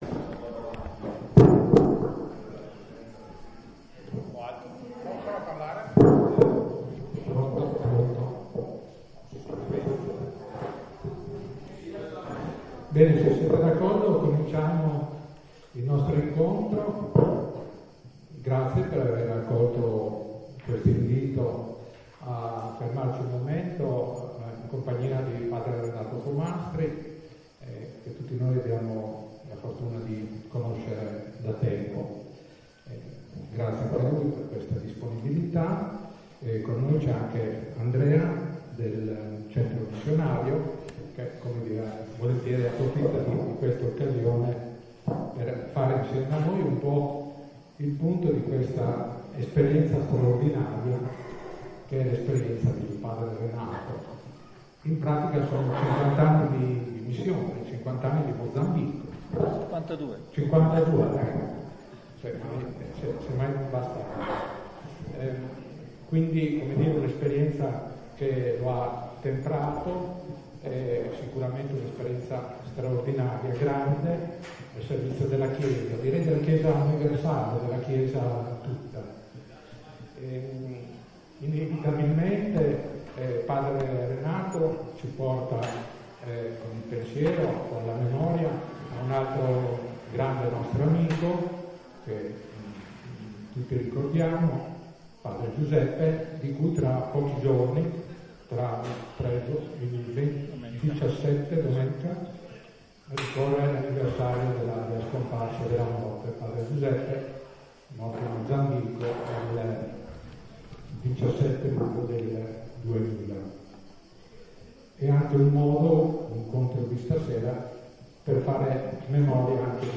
Ecco la registrazione audio dell'incontro.